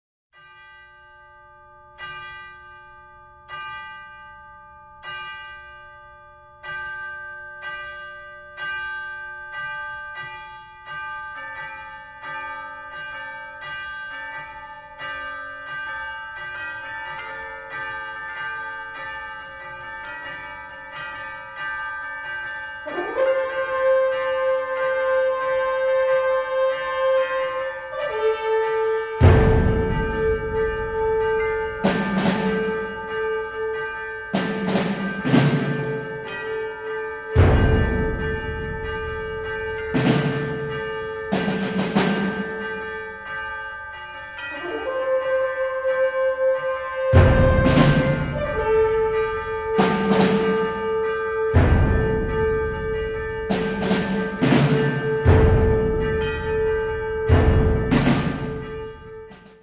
Unterkategorie Zeitgenössische Bläsermusik (1945-heute)
Besetzung Ha (Blasorchester)
Off-Stage Band